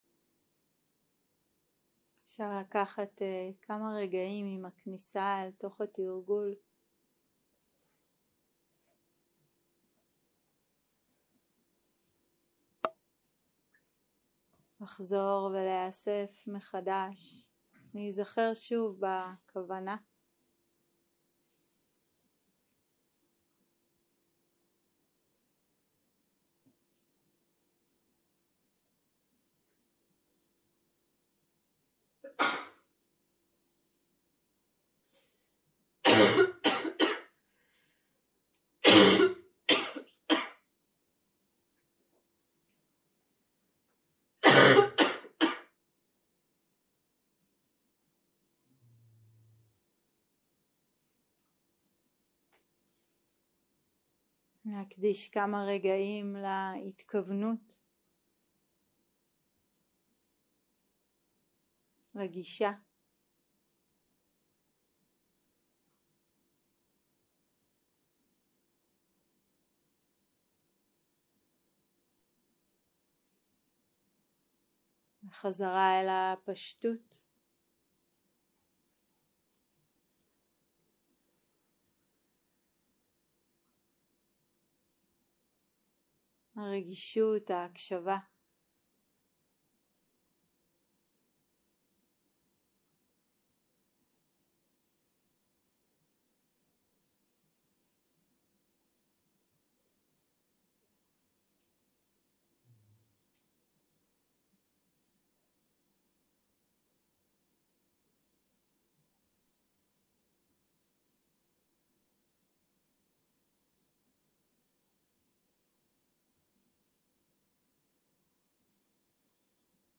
יום 1 - הקלטה 1 - ערב - מדיטציה מונחית - אני כאן